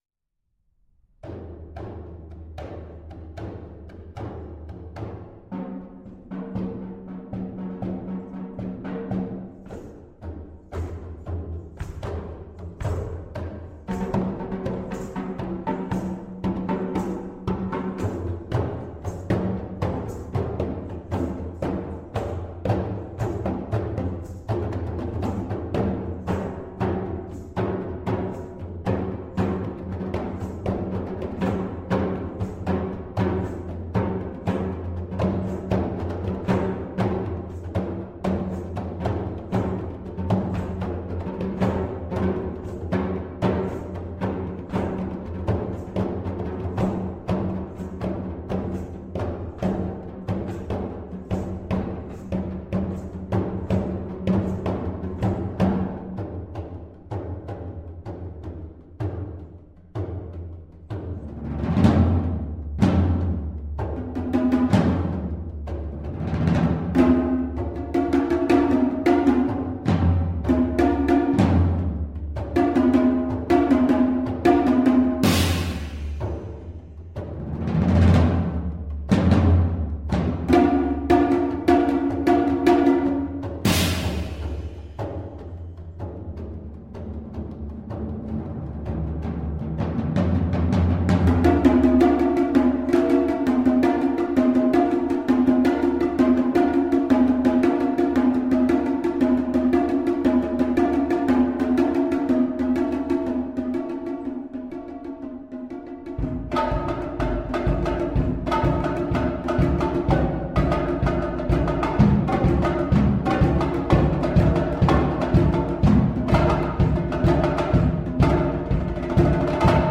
Genre: Duet for 2 Percussion
# of Players: 2